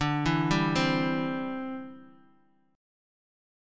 D7sus2sus4 Chord